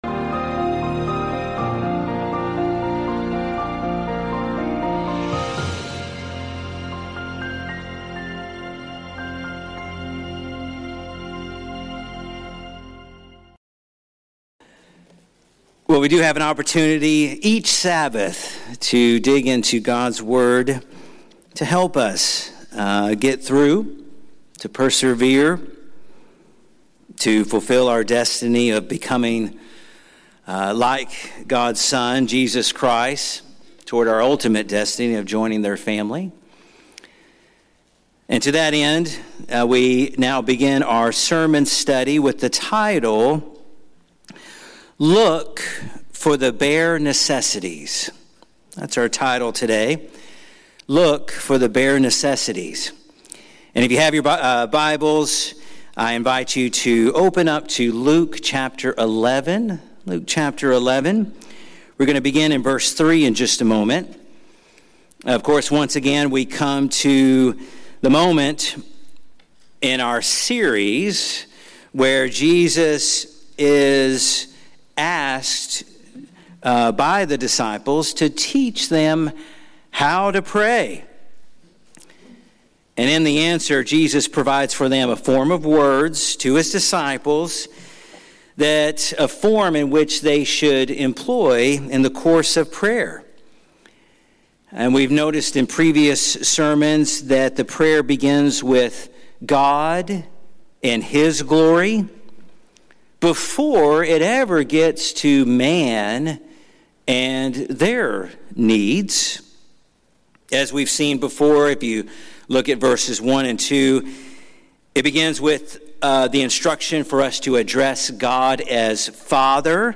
This sermon reminds us that when Jesus teaches us to pray for “daily bread,” He calls us to trust God for the bare necessities of life and to depend on His Father as our Provider. Rooted in God’s faithfulness shown through the manna in the wilderness, this prayer trains our hearts toward daily dependence, contentment, and gratitude.